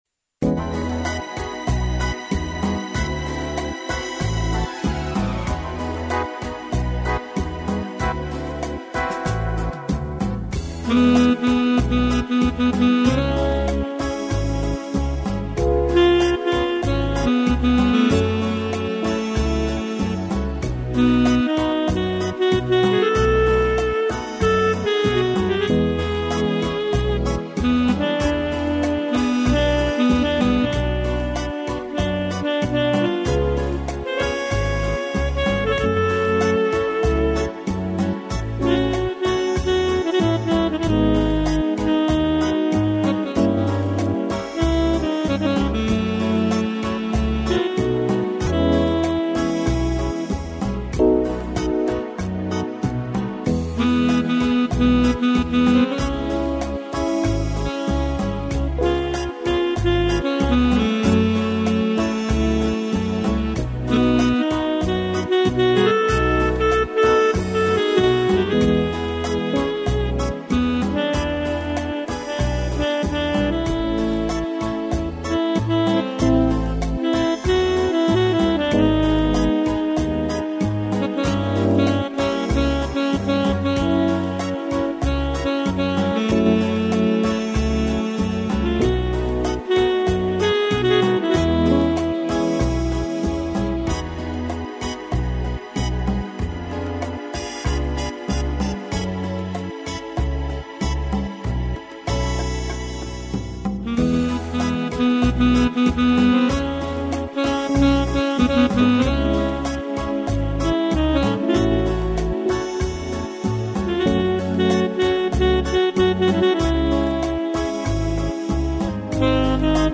violão
piano
instrumental